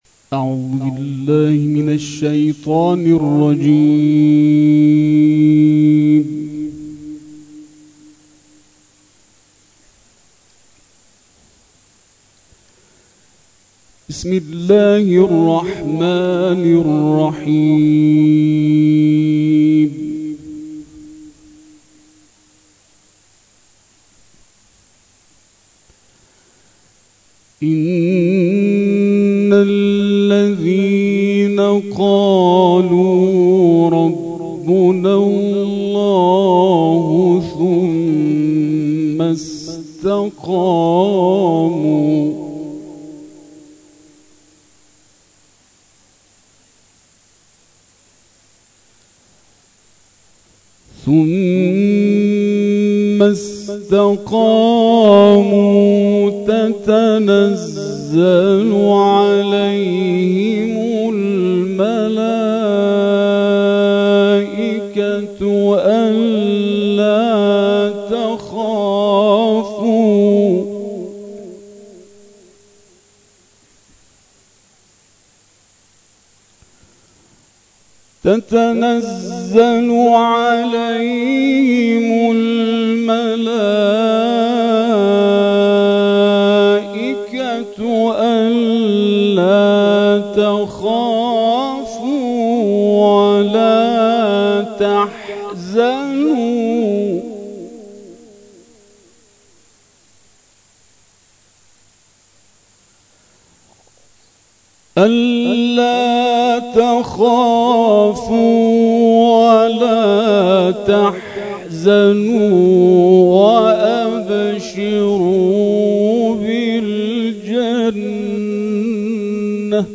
گروه جلسات و محافل: کرسی‌های تلاوت نفحات‌القرآن، در هفته گذشته با هدف جمع آوری کمک جهت زلزله زدگان کرمانشاه، با حضور قاریان ممتاز در مساجد و حسینیه‌های تهران و شهرری برگزار شد.